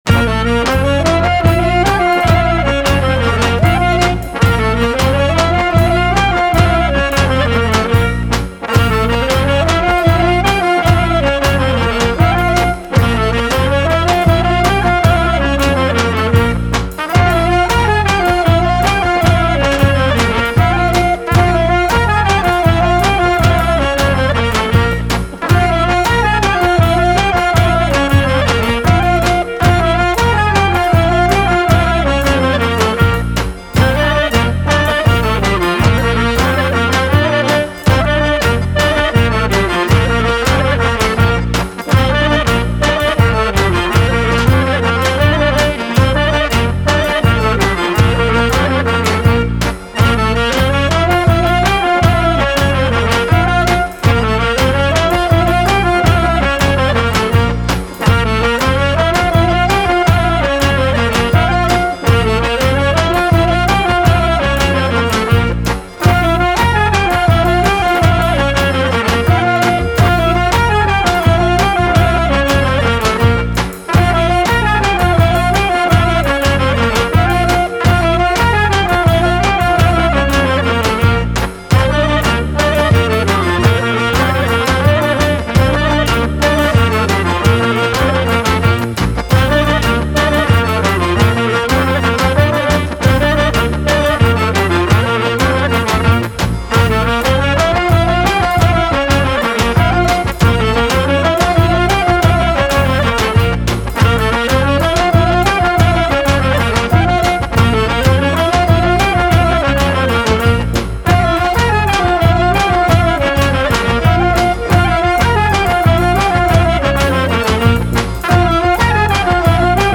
со оркестар